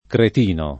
cretino [ kret & no ]